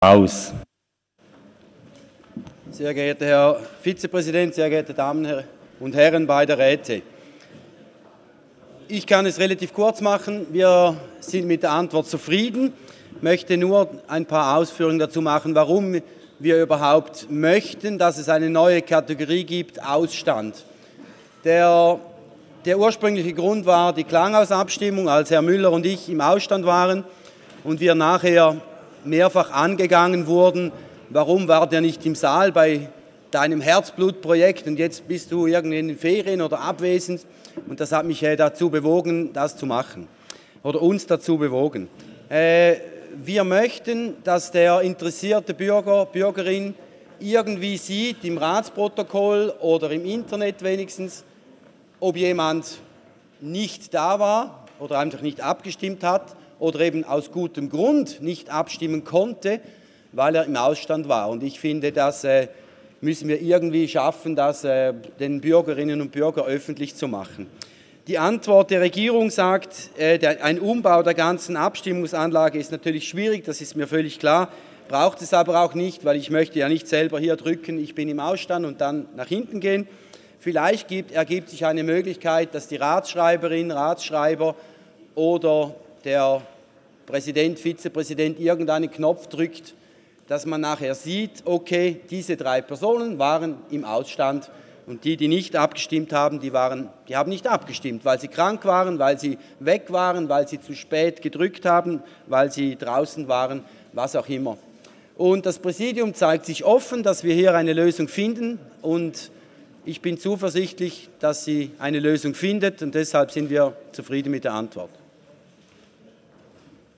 18.9.2019Wortmeldung
Session des Kantonsrates vom 16. bis 18. September 2019